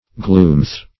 Meaning of gloomth. gloomth synonyms, pronunciation, spelling and more from Free Dictionary.